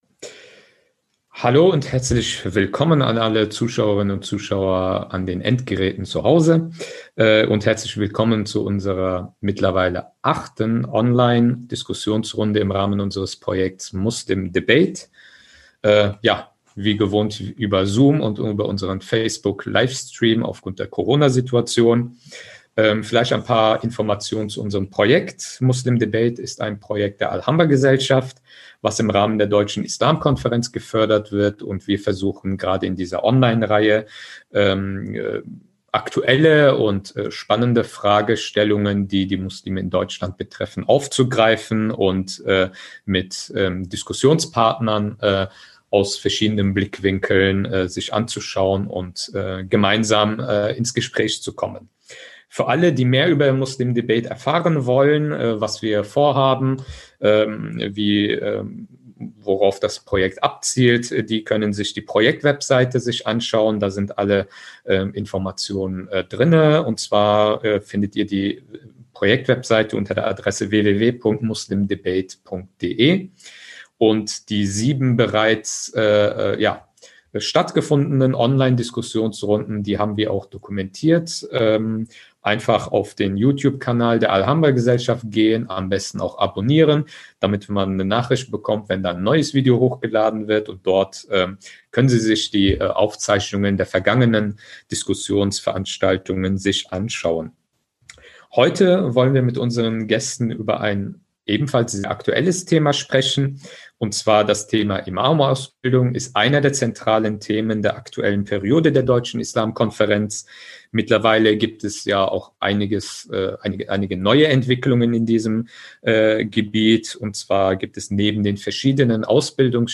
Wir wollen im Rahmen unserer Online-Diskussionsreihe darüber ins Gespräch kommen, was jenseits der politischen und gesellschaftlichen Debatte über die Imamausbildung die Bedarfe der Gemeinden sind. Wie kann ein Berufsbild eines Imams oder Imamin aussehen? Wie sollte die Ausbildung konkret inhaltlich gestaltet sein?